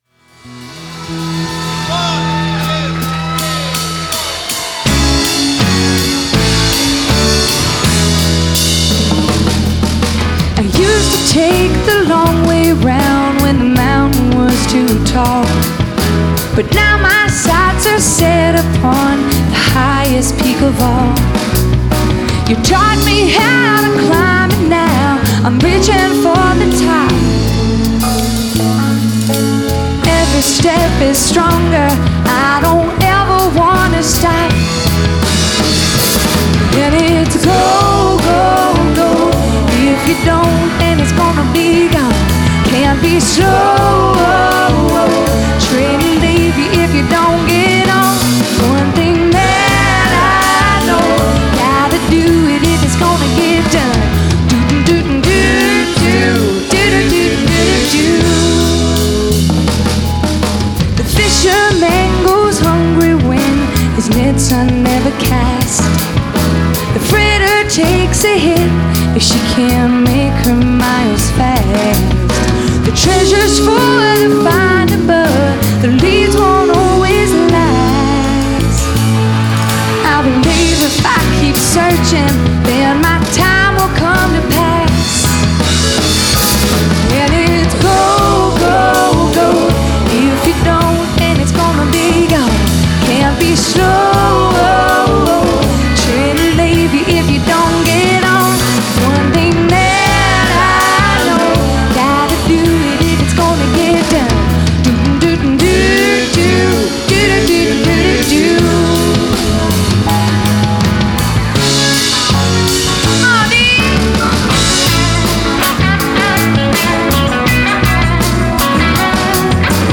バンドレコーディング向けマルチトラックのミックスサービスとなります。
マルチトラックサンプルミックス